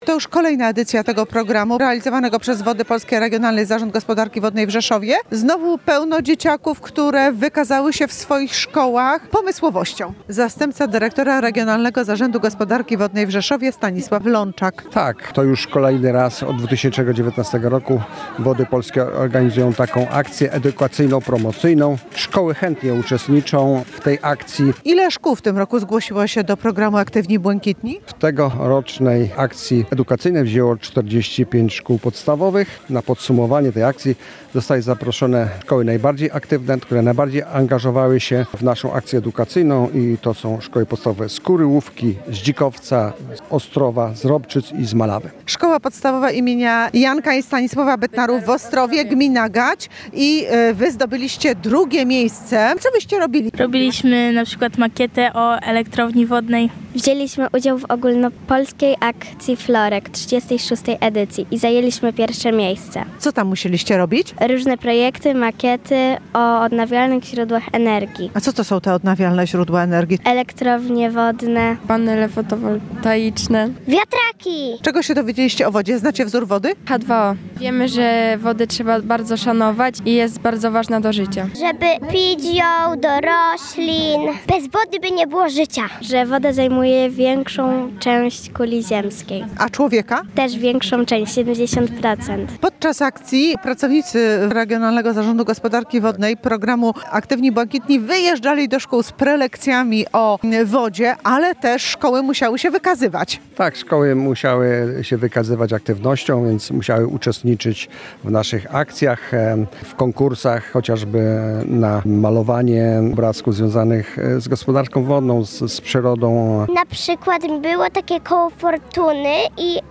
Na podsumowanie trzeciej edycji programu zaproszono 5 najaktywniejszych podstawówek nad Zalew w Wilczej Woli.
Relacja